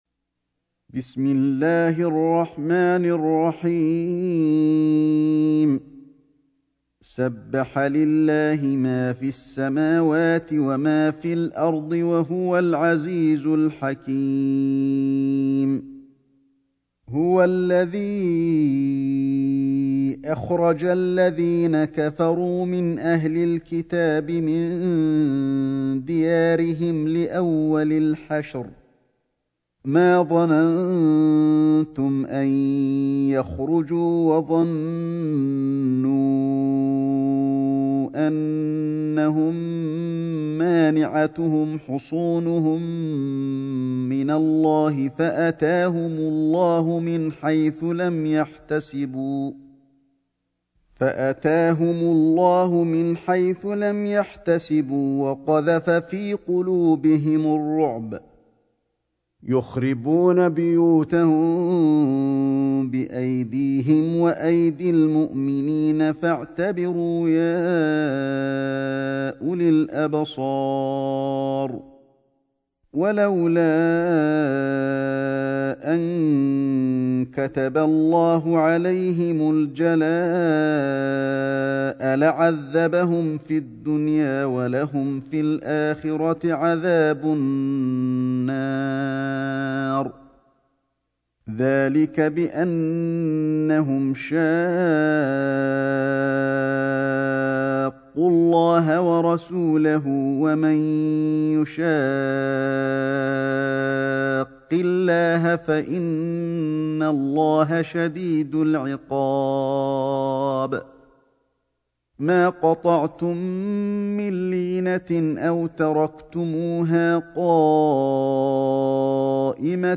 سورة الحشر > مصحف الشيخ علي الحذيفي ( رواية شعبة عن عاصم ) > المصحف - تلاوات الحرمين